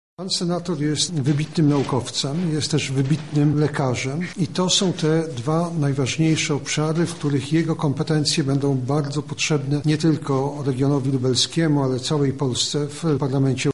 wicepremier Jarosław Gowin